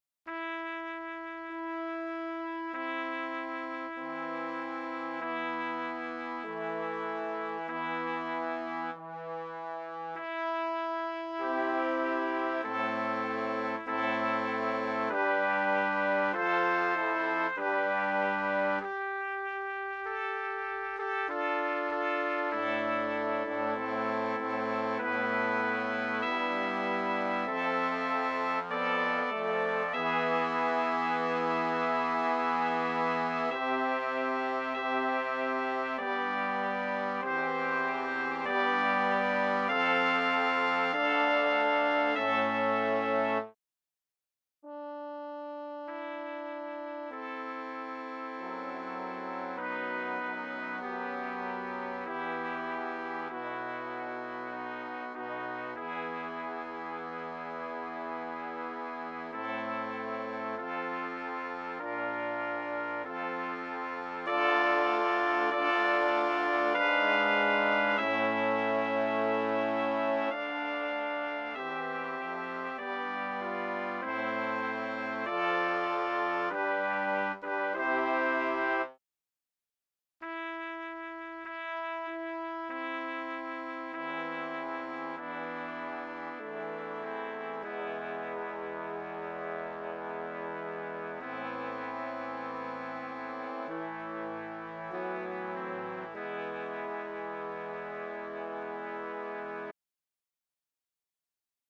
BRASS QUINTET
LENTEN MOTET